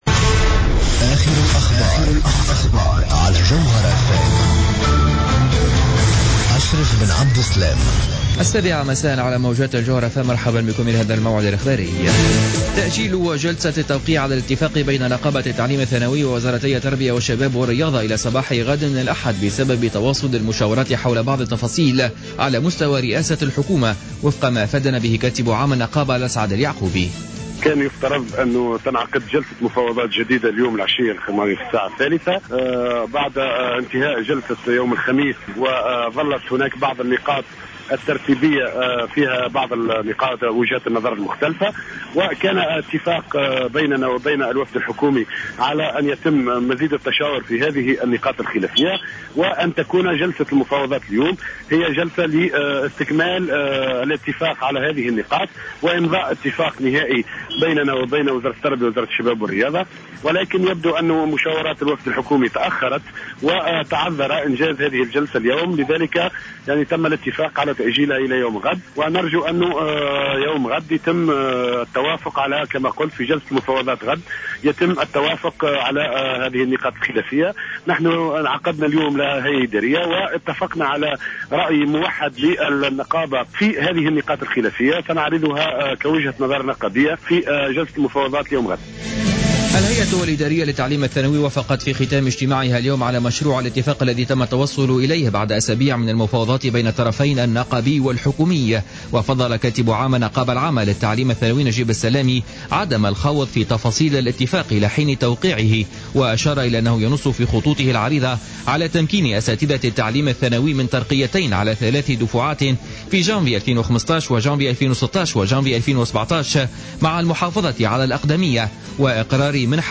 نشرة أخبار السابعة مساء ليوم السبت 4 أفريل 2015